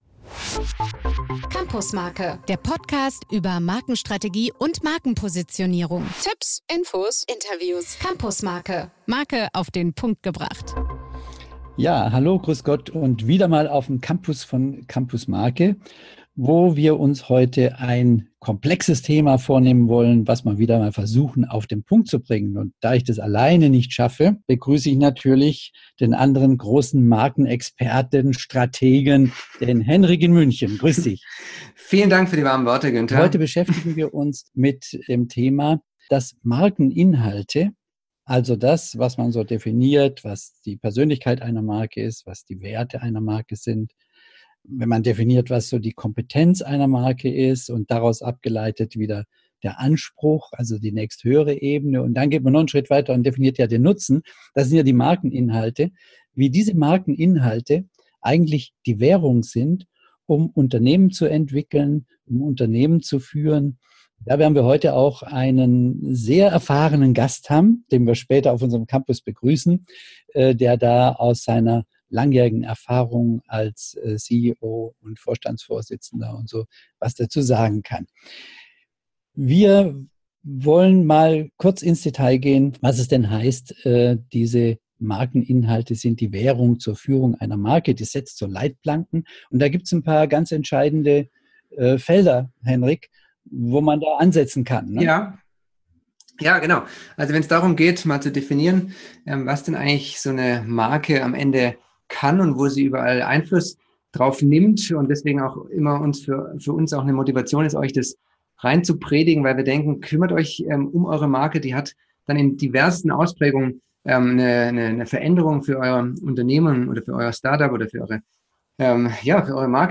All diese Punkte diskutieren wir mit unserem heutigen Interviewgast auf unserem Campus von Campusmarke.